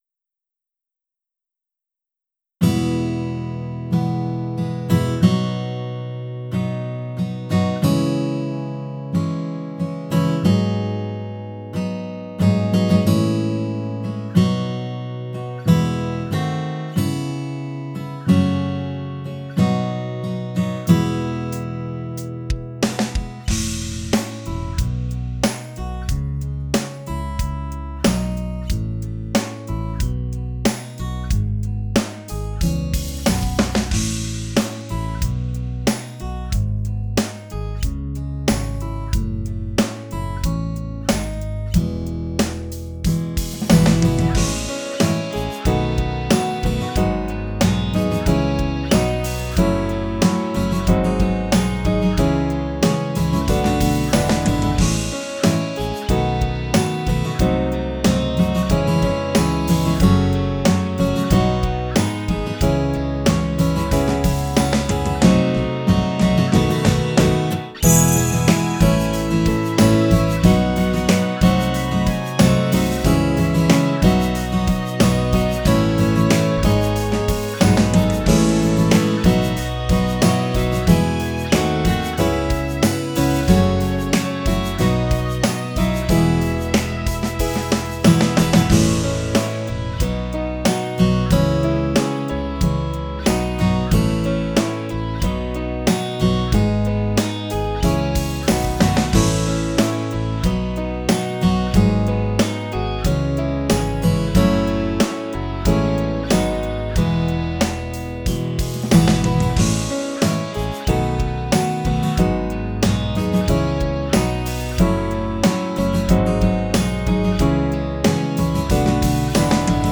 インスト音源 / コード譜 配布
私は上記の楽曲を参考に楽器を追加し、奏法等に試行錯誤しながらも何とか仕上げに入りました。